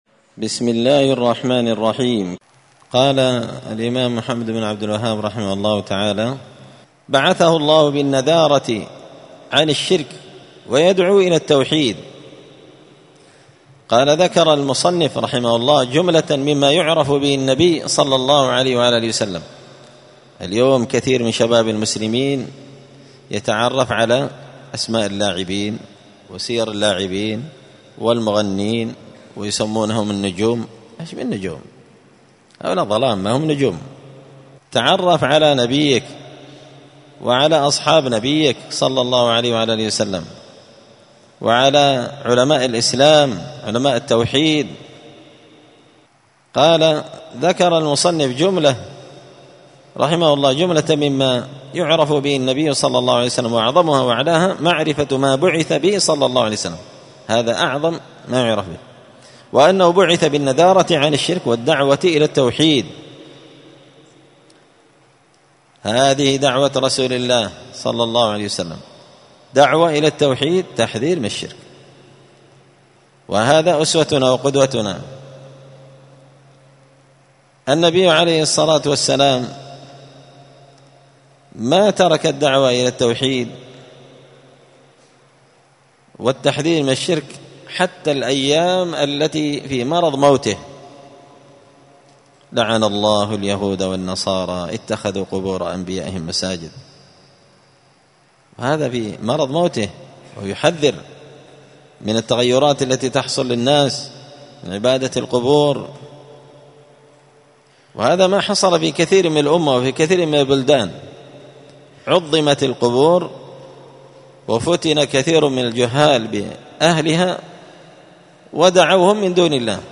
دار الحديث السلفية بمسجد الفرقان بقشن المهرة اليمن
*الدرس الثالث والثلاثون (33) من قوله {بعثه الله بالنذارة عن الشرك ويدعوا إلى التوحيد…}*